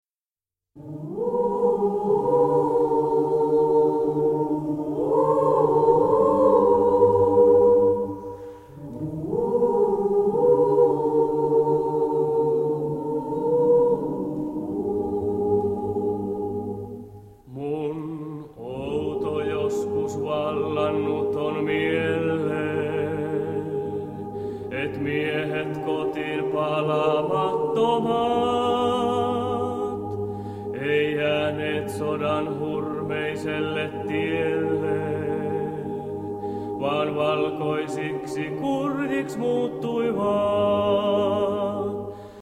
Soloisti